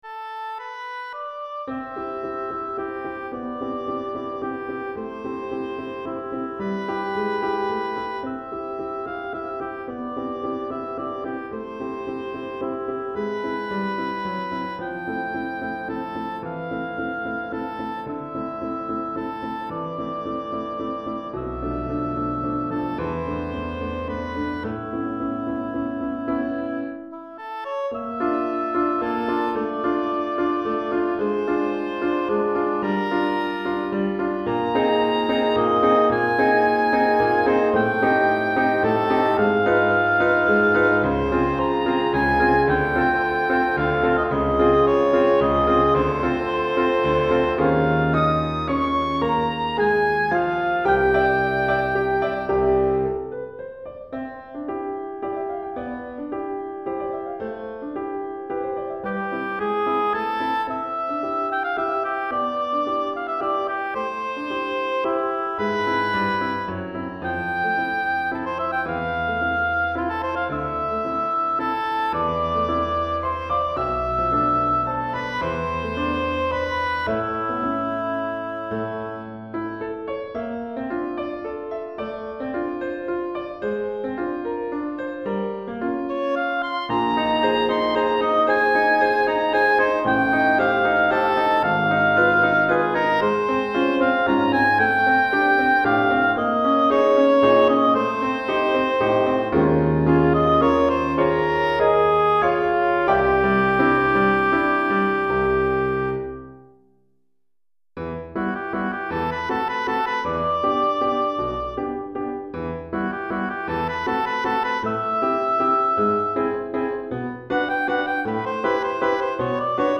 Hautbois et Piano